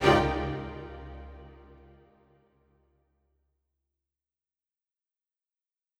an orchestra performing
Strings Hit 3 Staccato
A staccato is a short and fast sound that any orchestral instrument can make.  In this sample, you hear four sections of four different instruments from the orchestra which are violins, violas, violoncellos and double basses.
Strings-Hit-3-Staccato.wav